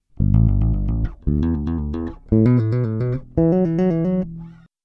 Combining a string of hammer-ons and pull-offs creates a trill: a rapid fluctuation between two notes.
Bass Trill Example
Here a riff is played using trills, combining hammer-ons and pull-offs.
bt4_3_Electric_Bass_Trill_Example.mp3